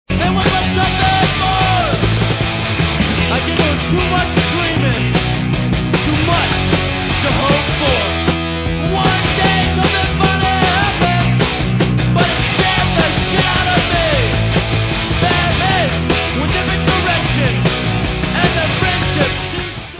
sledovat novinky v oddělení Rock/Hardcore